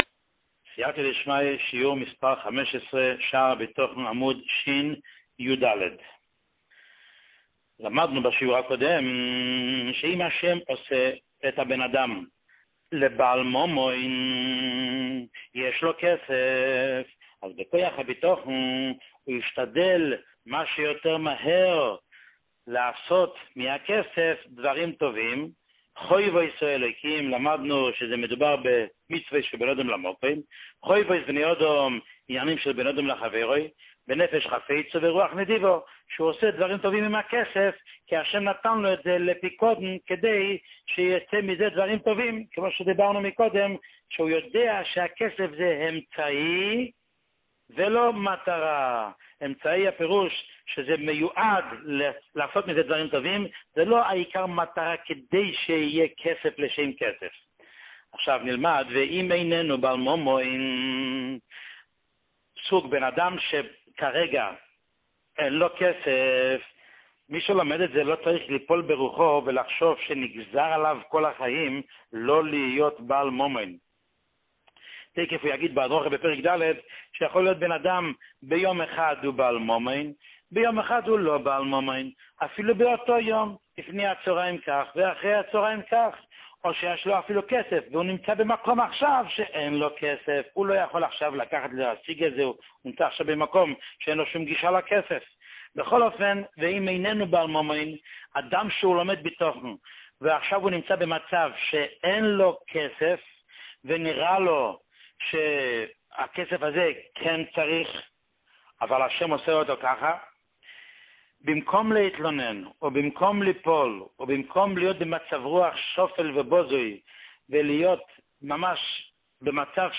שיעור 15